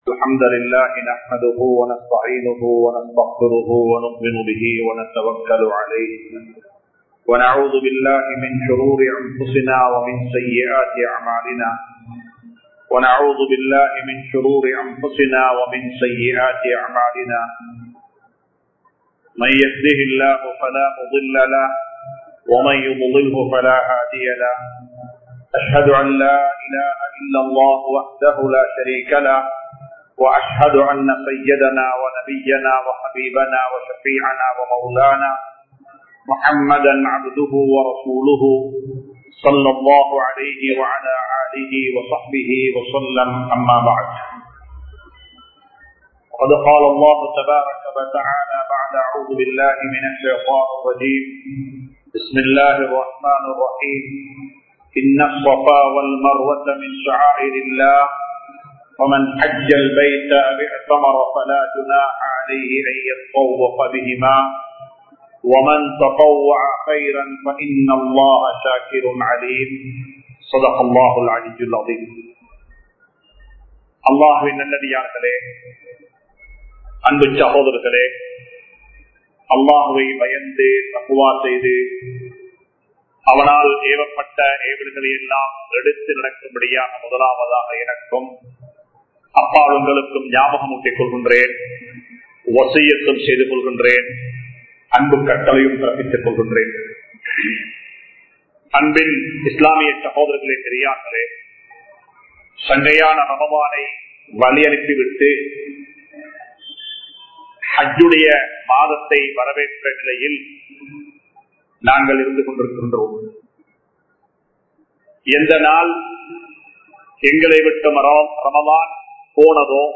Kalvi Katpathan Noakkam Enna? (கல்வி கற்பதன் நோக்கம் என்ன?) | Audio Bayans | All Ceylon Muslim Youth Community | Addalaichenai
Majma Ul Khairah Jumua Masjith (Nimal Road)